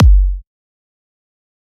EDM Kick 2.wav